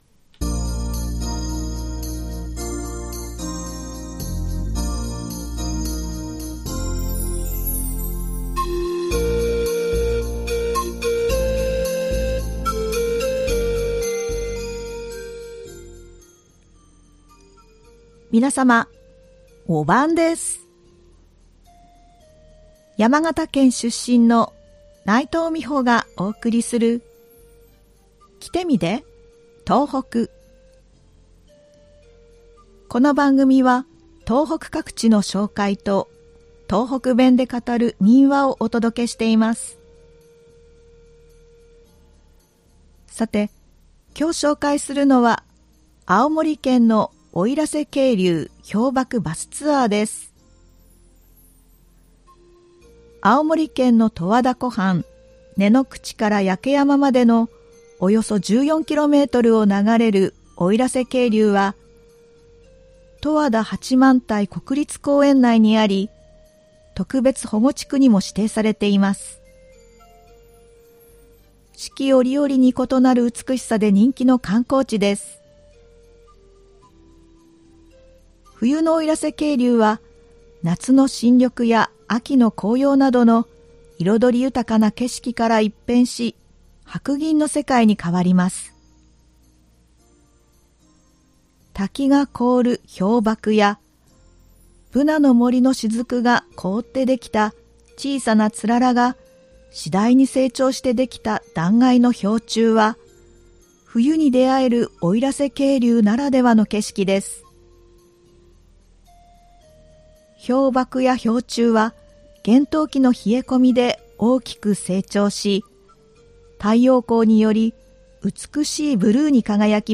ではここから、東北弁で語る民話をお送りします。今回は青森県で語られていた民話「猫とかぼちゃ」です。